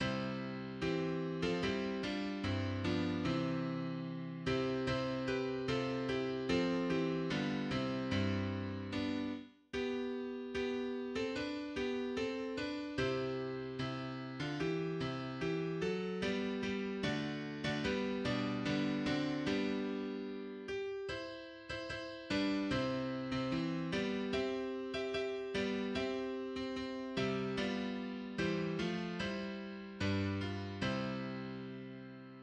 { \new PianoStaff << \language "english" \new Staff << \new Voice \relative c'' { \set Score.tempoHideNote = ##t \override Score.BarNumber #'transparent = ##t \tempo 2 = 74 \voiceOne \clef treble \key c \major \time 2/2 c2 g4. a8 | g4( f) e( d) | c2. g'4 | c( b) a( g) | a( g) f( e) | e2( d4) r4 | \break g2 g4. a8 | b4 g a b | c2 r2 | r1 | d2 d4. d8 | \break b4 d d, fs | g2. g4 | c4. c8 c4 b | c2. d4 | \break e4. e8 e4 d | e2. e4 | d2 c | c b | c1 } \new Voice \relative c' { \voiceTwo e2 g4. f8 | e4( d) c( b) | c2. e4 | e( g) f( e) | c2 b4( c) | c2( b4) s4 | r1 | r | g'2 e4. f8 | g4 e g a | g g fs4. fs8 | g4 d b d | d2. g4 | e4. e8 e4 d | e2. g4 | g4. g8 g4 g | g2. g4 | f2 g4( f) | e2 d4( f) | e1 } >> \new Staff << \new Voice \relative c' { \clef bass \key c \major \time 2/2 \voiceOne g2 c4. c8 | c4( g ~ g) f | e2. c'4 | c2 c | c g | g2. r4 | b2 b4. c8 | d4 b c d | e2 r2 | r1 | b2 a4. a8 | b4 g g c | b2. r4 | r2 r4 d | c4. c8 c4 b4 | c2. b4 | c4. c8 c4 g | b2 g4( a) | g2 g | g1 } \new Voice \relative c { \voiceTwo c2 e4. f8 | g2 g,2 | c2. c4 | c2 c | f4( e) d( c) | g2. r4 | r1 | r | c2 c4. d8 | e4 c e fs | g g d4. d8 | g4 b, d d | g2. r4 | r2 r4 g | c,4. c8 e4 g4 | c2. g4 | c4. c8 c4 c, | g'2 e4( f) | g2 g, | c1 } >> >> }